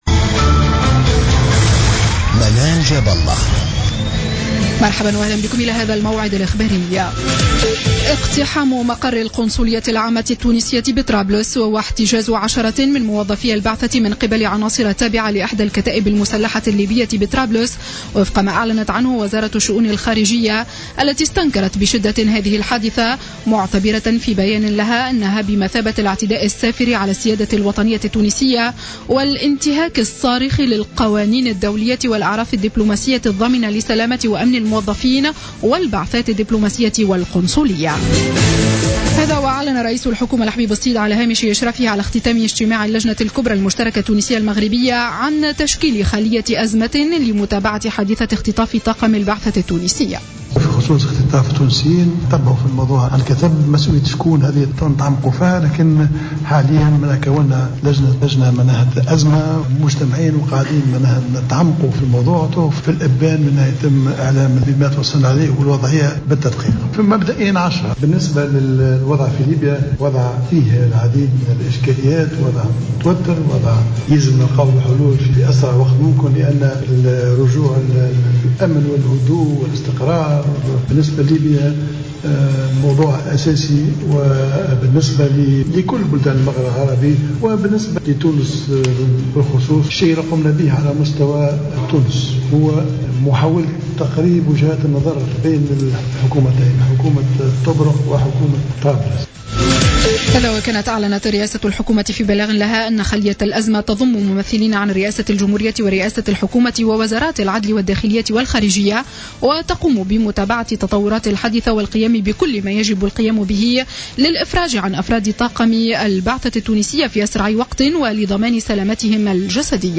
نشرة أخبار السابعة مساء ليوم الجمعة 12 جوان 2015